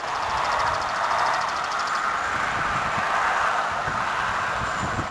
EVP'S ELECTRONIC VOICE PHENOMENA
I had old faithful, my older digital recorder, and my new expanded memory card Sony Cybershot.
Those are 3 better then average EVP's.  The First being the best, the last either being wind or the whine of a motorcycle.